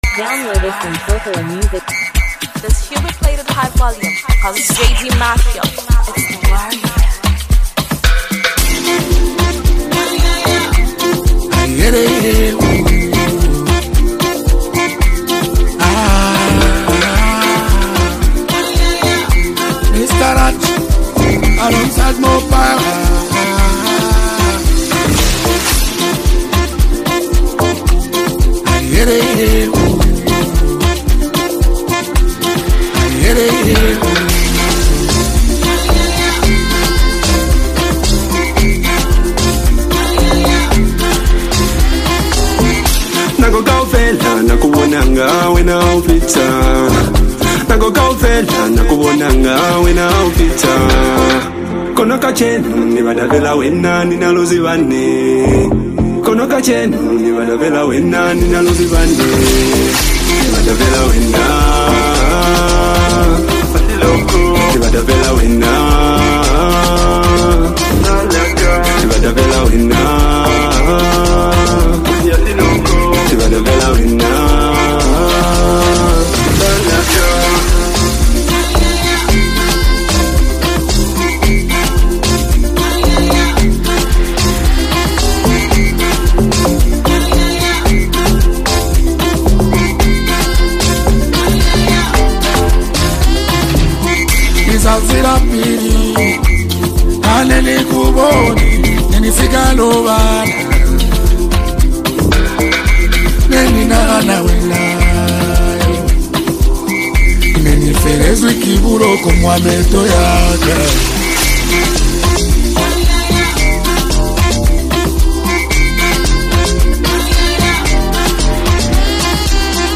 captivating Afrobeat-inspired single